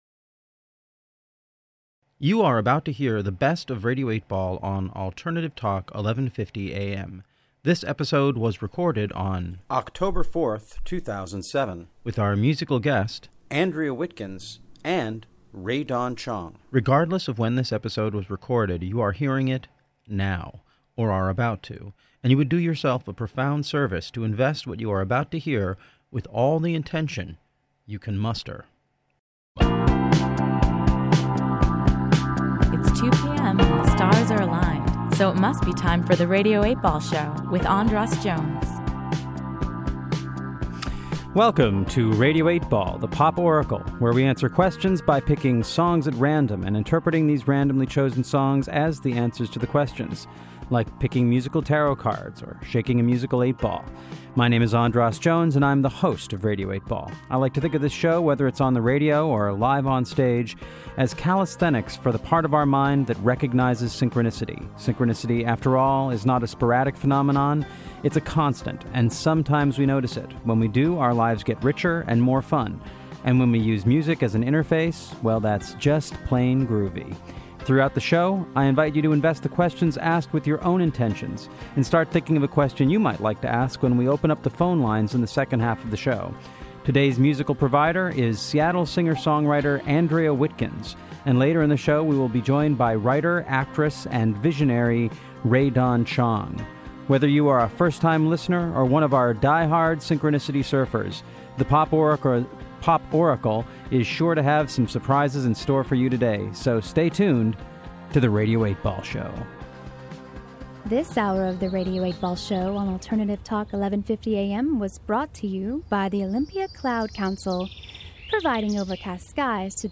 Best of R8B on 1150AM from October 18th, 2007